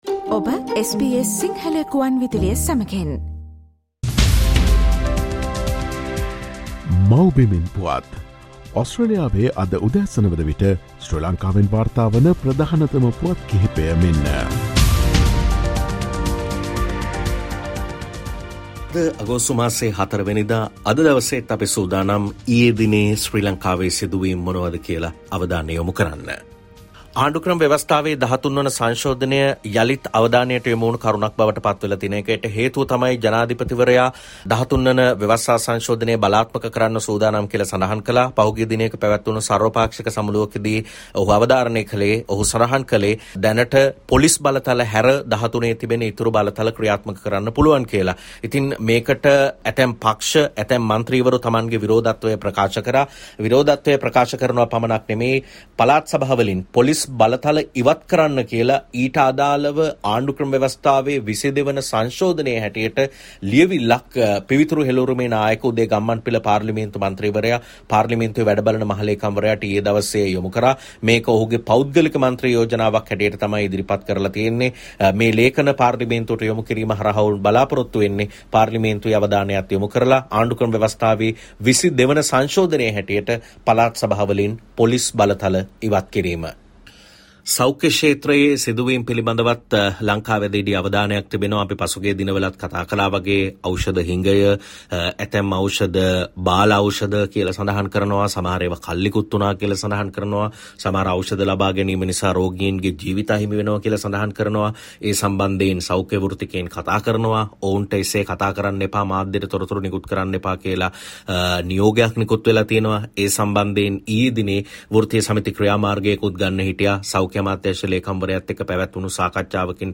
SL News report Aug 04: A vote of no confidence is being brought against Minister Keheliya, MP Kavinda says